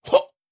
Effort Sounds
10. Effort Grunt (Male).wav